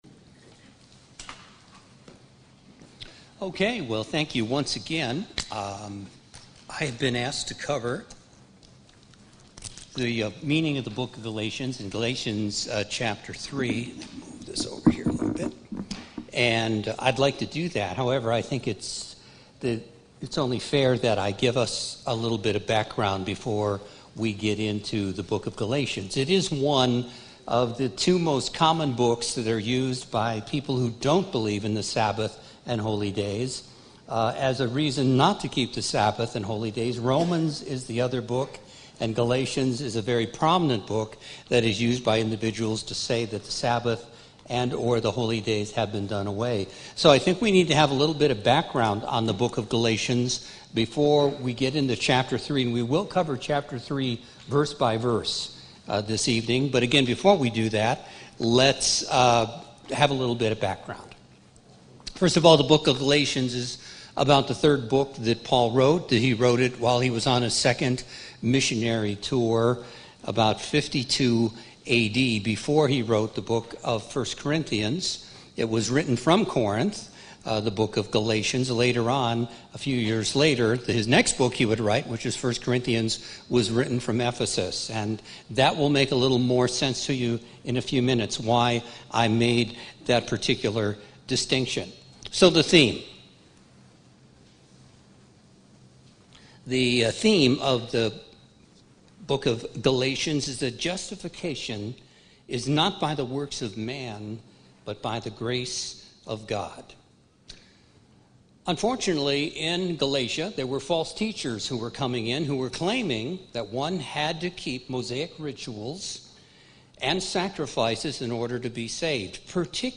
Bible Study: Meaning of the Book of Galatians Part I 10/7/2020 - Feast of Tabernacles Berlin, OH 2020